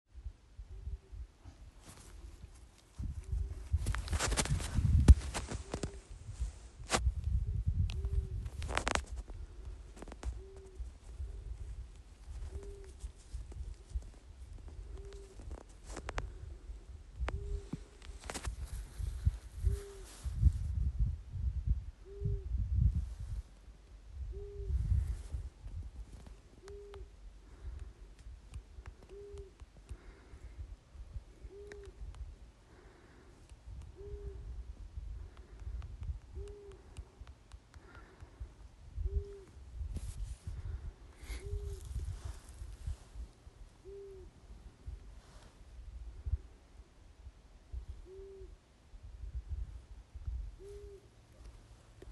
Ausainā pūce, Asio otus
Administratīvā teritorijaTukuma novads
PiezīmesAusainā pūce tika novērota gan lidojumā, gan bija dzirdama vokalizējam